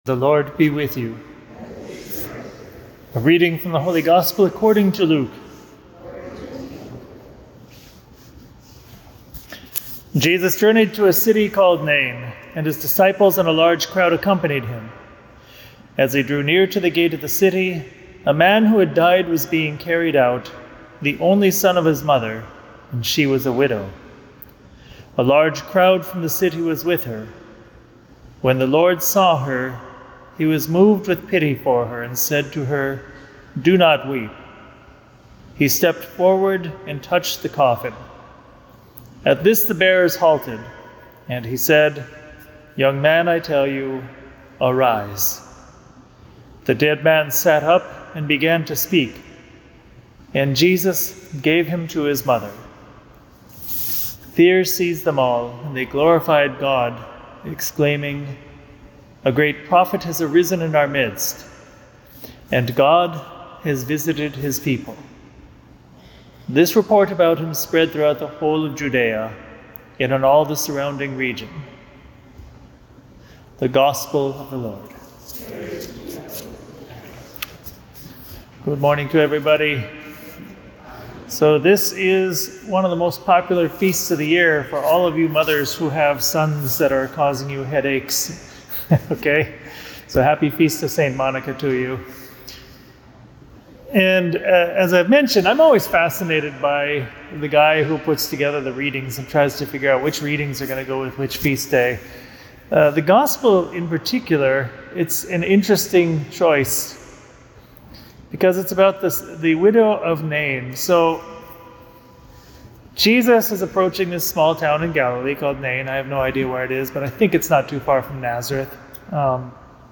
Homily
at Resurrection Parish